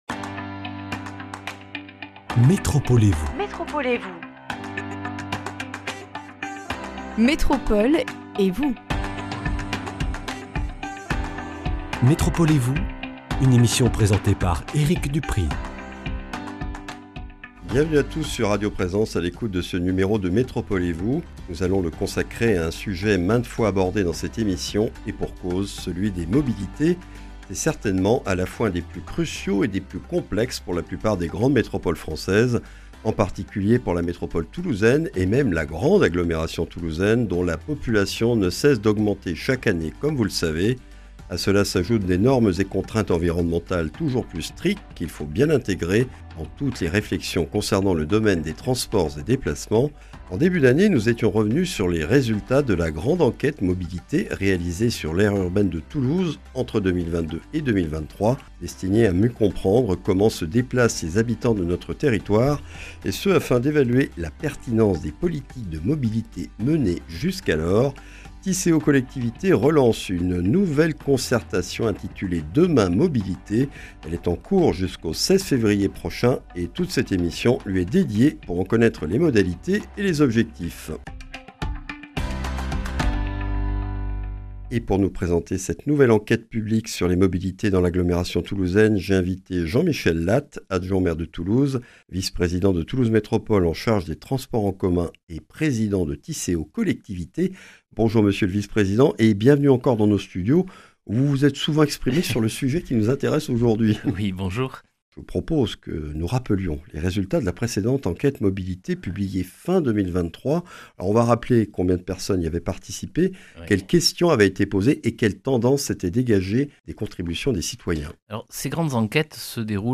Tisséo Collectivités a lancé la concertation publique "Demain Mobilités !" jusqu’au 16 février 2025 pour construire avec les citoyens métropolitains le Plan de Mobilité 2030/2040 de l’agglomération toulousaine. Des explications avec Jean-Michel Lattes, adjoint au maire de Toulouse, vice-président de Toulouse Métropole chargé des transports en commun, président de Tisséo Collectivités.